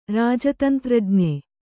♪ rāja tantrajñe